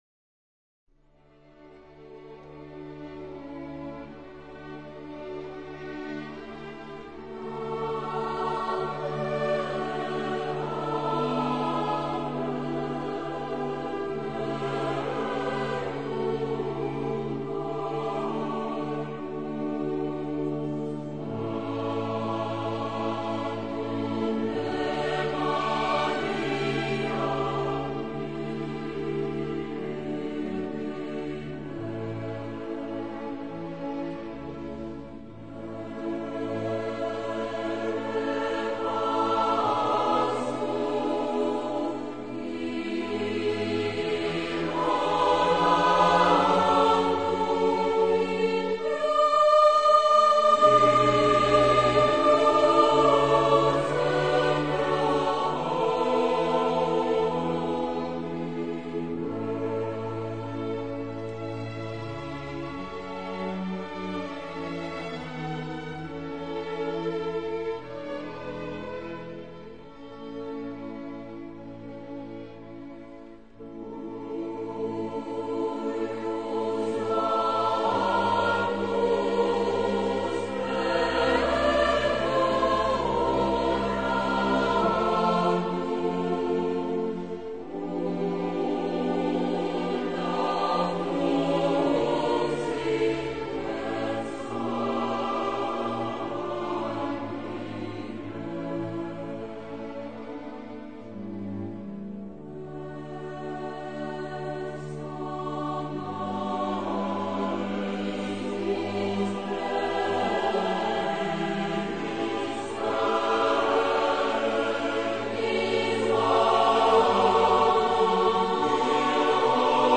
Rocca di Capri Leone, 21 novembre 2008
in occasione della "Peregrinatio Mariae"